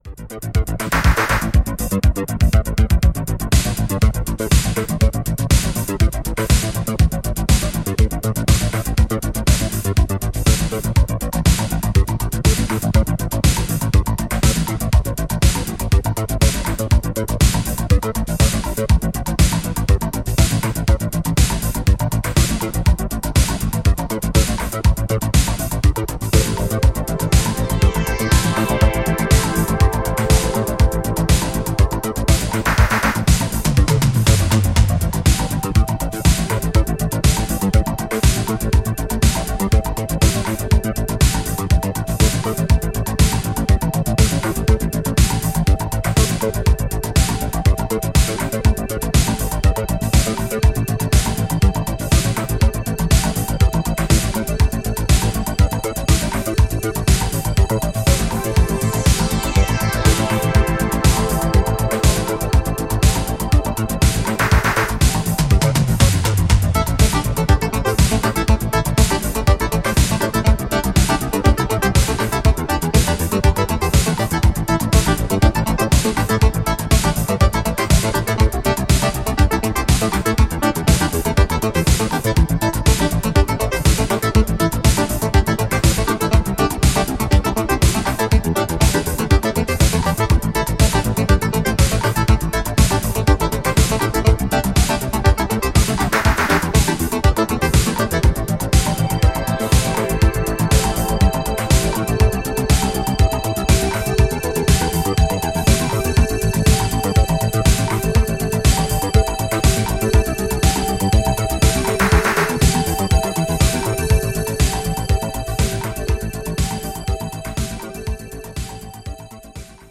supplier of essential dance music
House Outernational